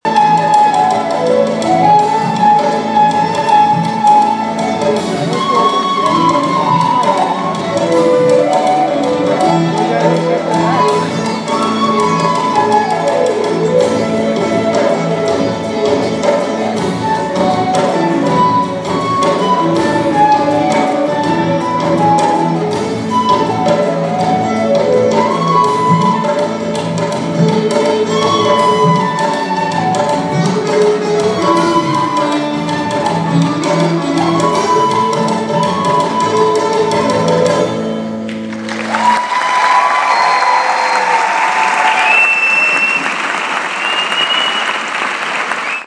Recorded on my phone